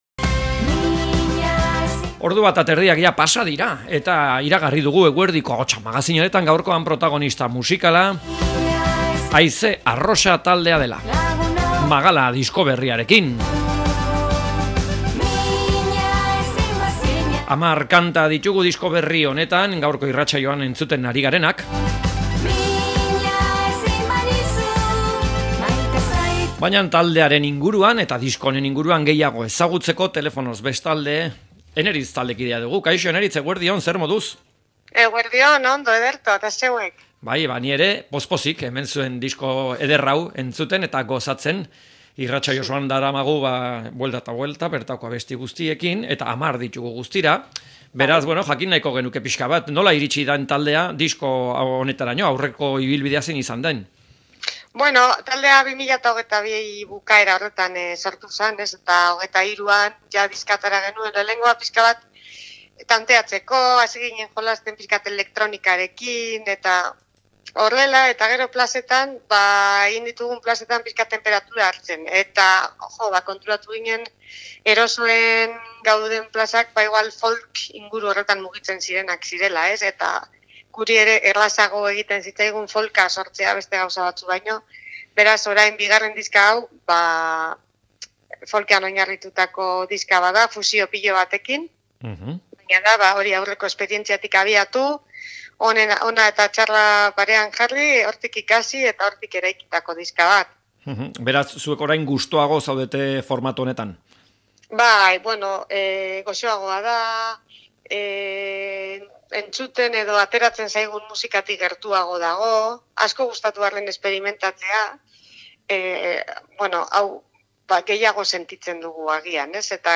Haize arrosari elkarrizketa
Elkarrizketak